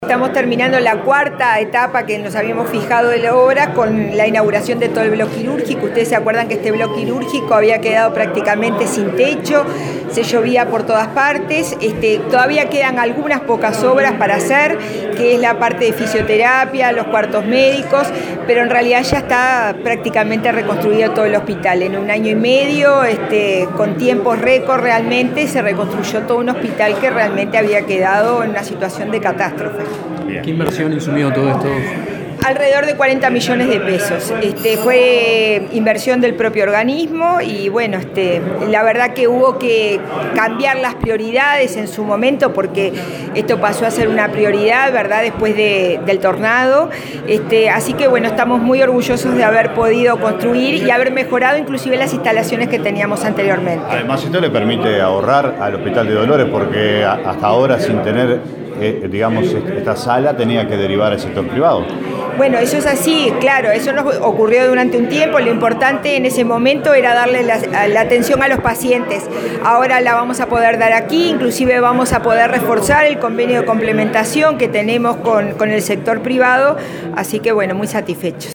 “En un año y medio, con tiempos récord, se reconstruyó el Hospital de Dolores, que había quedado en situación de catástrofe”, afirmó la presidenta de ASSE, Susana Muñiz, al inaugurar este viernes 11 el block quirúrgico. Informó que las obras demandaron una inversión de $ 40 millones.